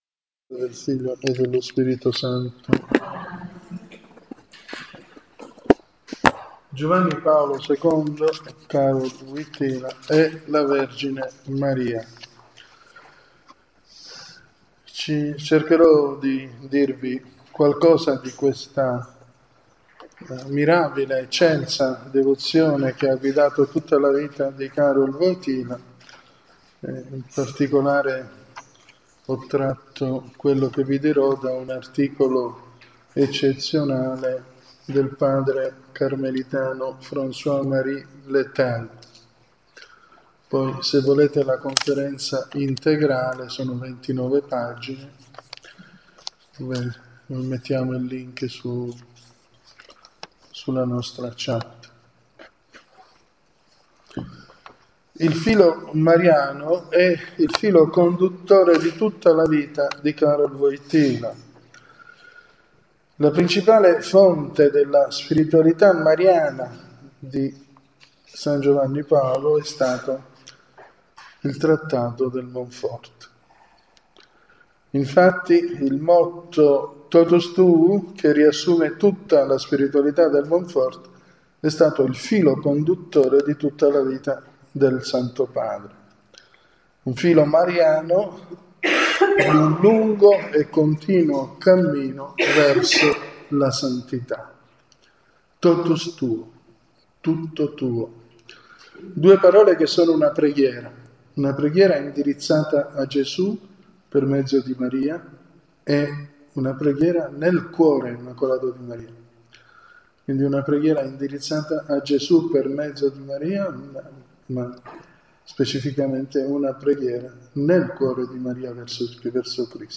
REGISTRAZIONI DELLE CONFERENZE E DELLE OMELIE